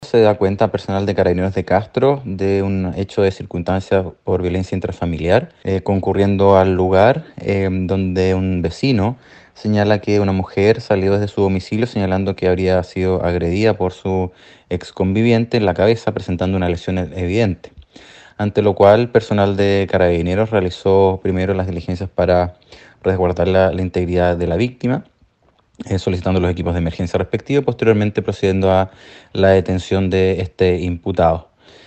El fiscal de Castro, Luis Barría, se refirió en primer lugar a los acontecimientos que quedaron al descubierto tras la denuncia que efectuaron diversas personas al fono 133 de Carabineros.
01-FISCAL-LUIS-BARRIA-VIF-CASTRO.mp3